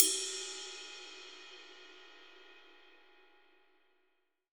RIDE 3.wav